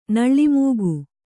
♪ naḷḷi mūgu